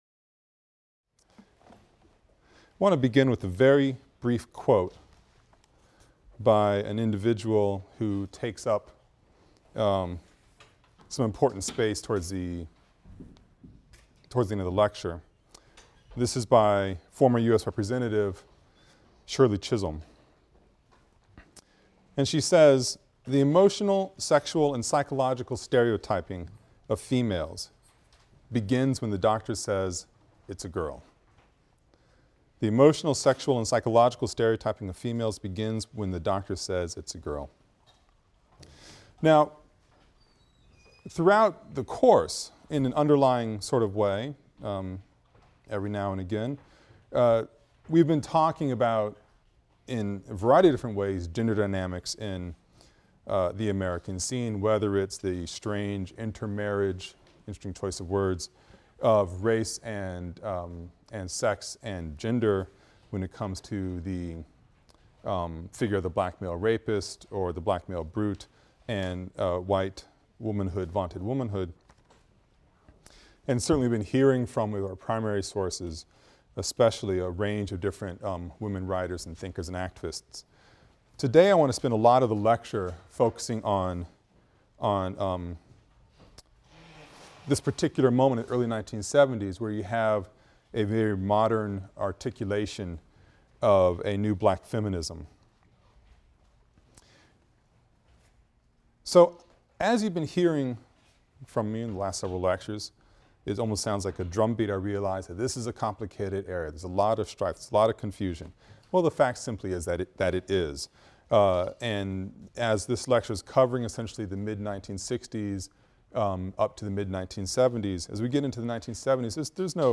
AFAM 162 - Lecture 21 - The Politics of Gender and Culture (continued) | Open Yale Courses